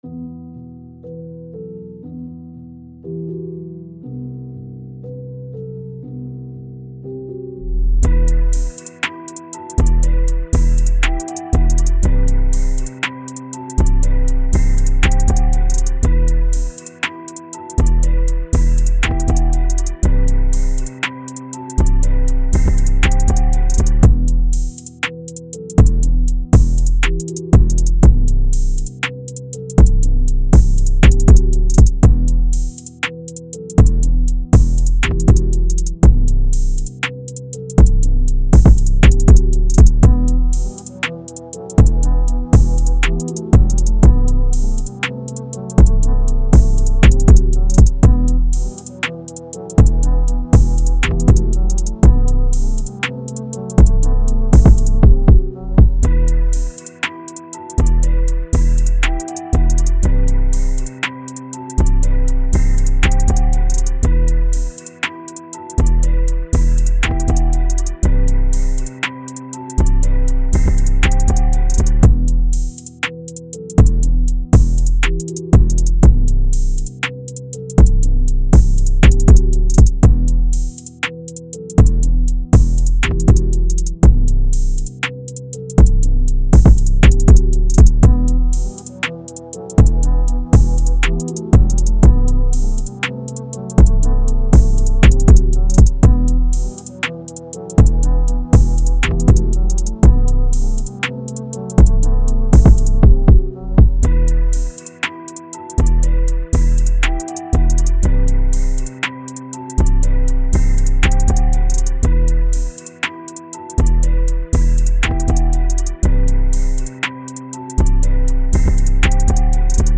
Hip Hop
E min